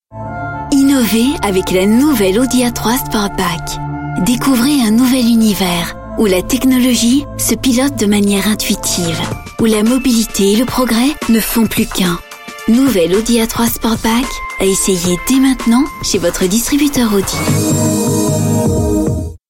Sprechprobe: Werbung (Muttersprache):
I adapt my voice to your needs. sweet, warm, young, serious, right, sensual, funny ...